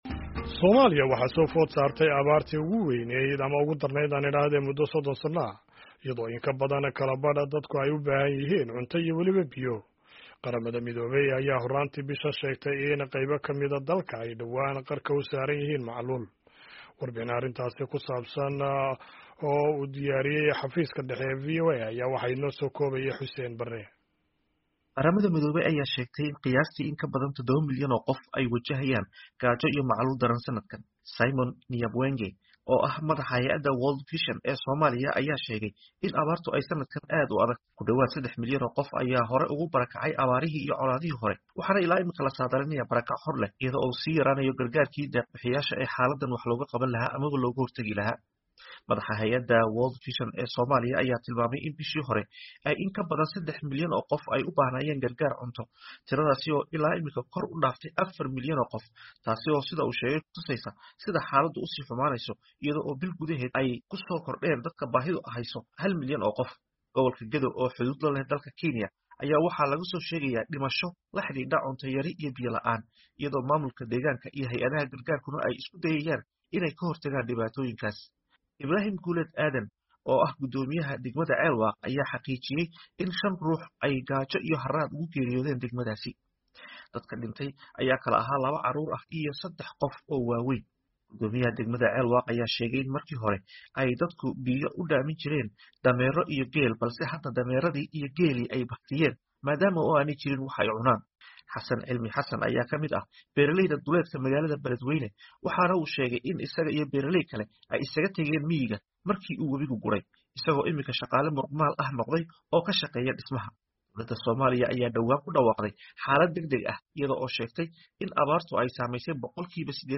Warbixin arrintaasi ku saabsan oo u diyaariyey xafiiska dhexe ee wararka VOA